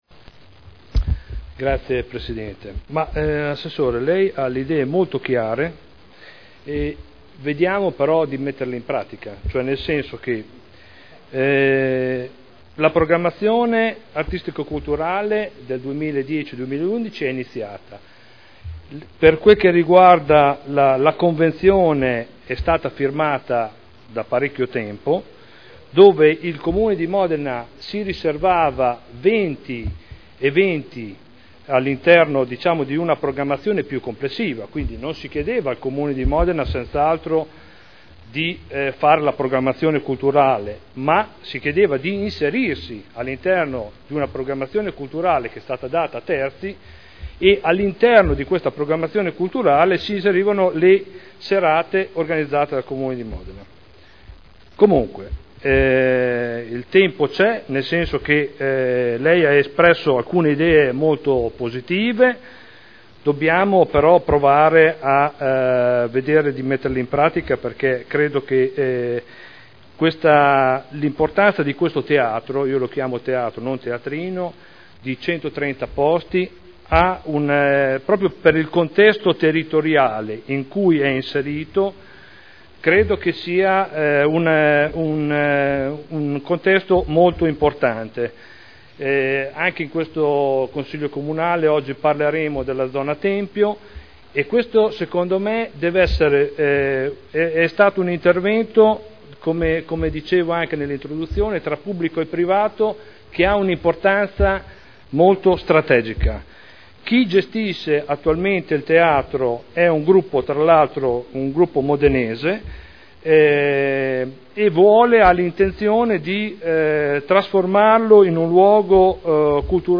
Stefano Prampolini — Sito Audio Consiglio Comunale
Seduta del 14/02/2011. Conclude interrogazione dei consiglieri Prampolini e Sala (P.D.) avente per oggetto: "Teatro Tempio”